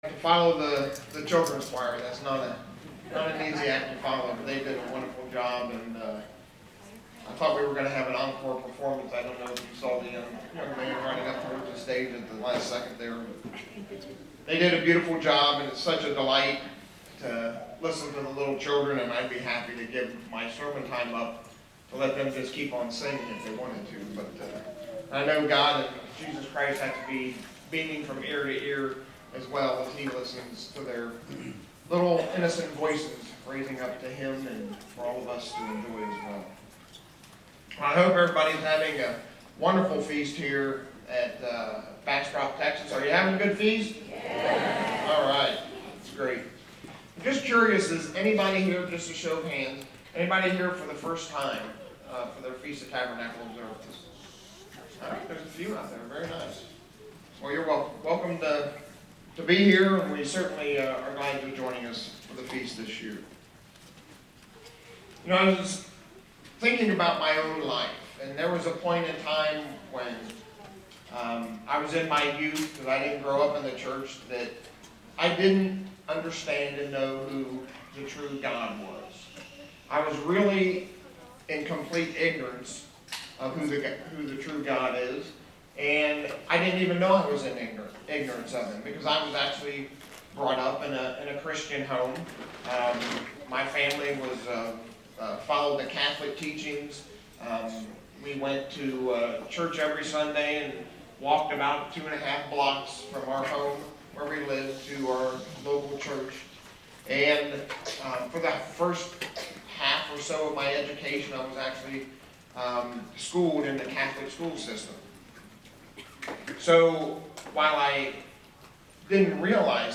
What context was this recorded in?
This sermon was given at the Bastrop, Texas 2022 Feast site.